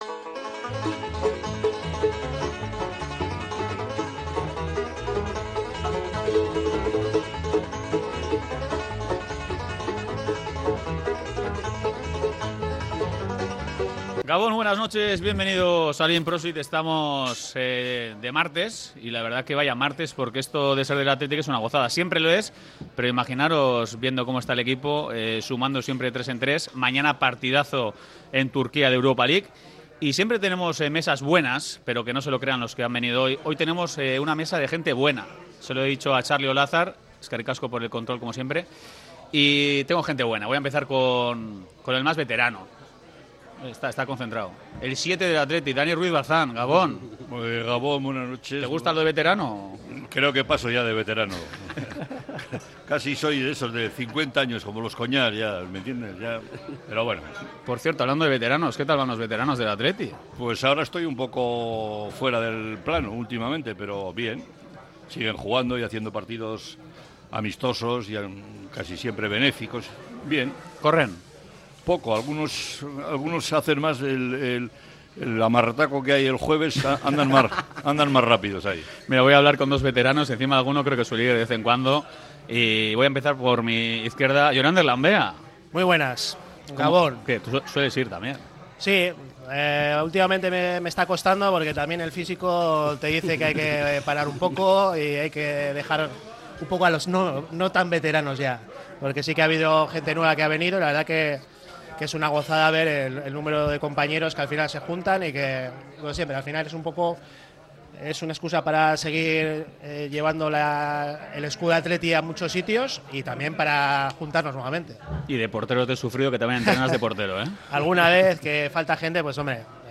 La mesa redonda del Ein Prosit ha reunido hoy martes